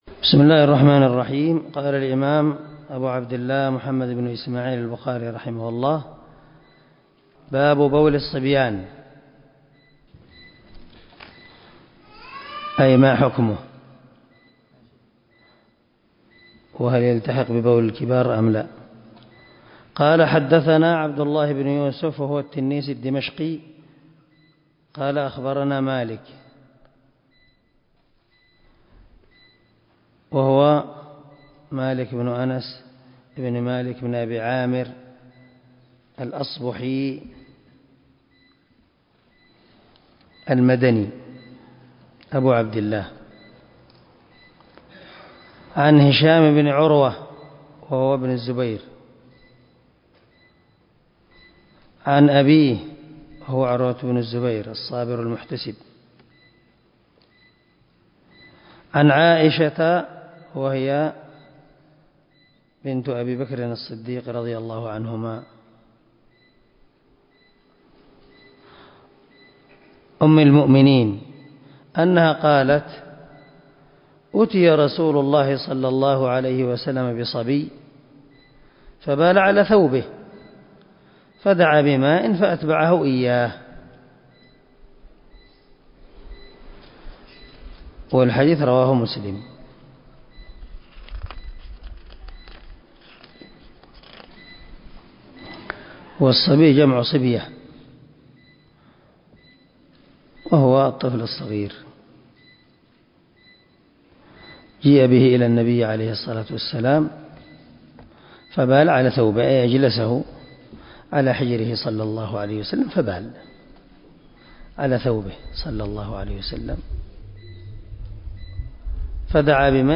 189الدرس 65 من شرح كتاب الوضوء حديث رقم ( 222 - 223 ) من صحيح البخاري
دار الحديث- المَحاوِلة- الصبيحة.